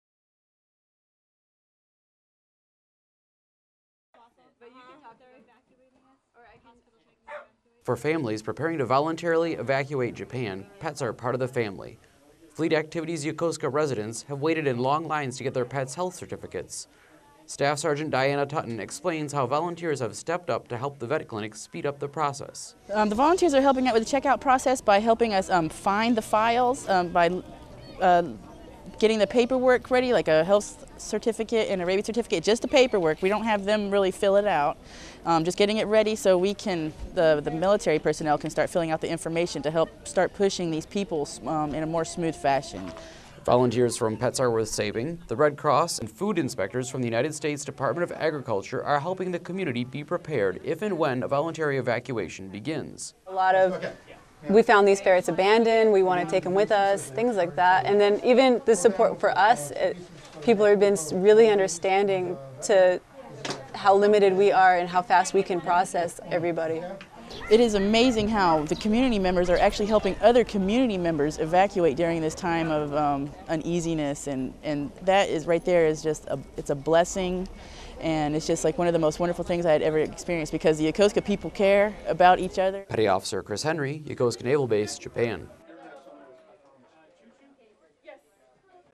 Yokosuka Narita Bus Transport - Radio Package